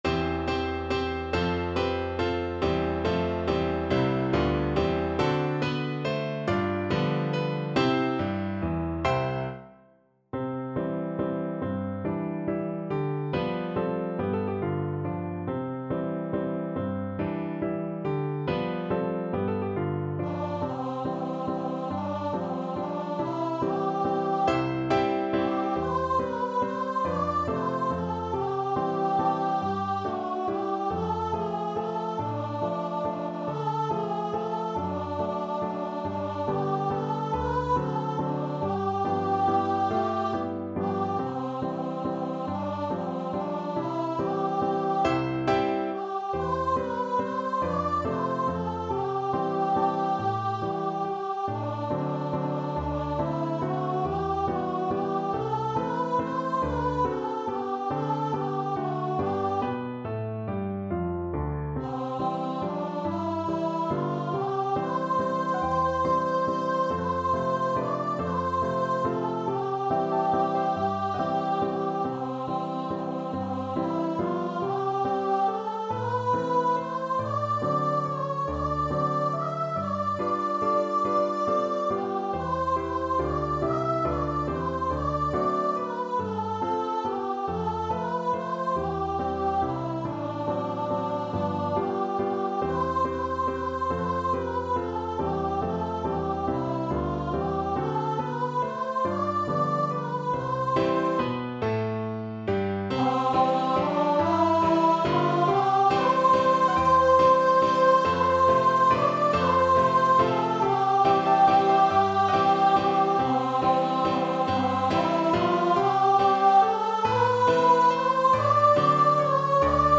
3/4 (View more 3/4 Music)
~ = 140 Tempo di Valse
Scottish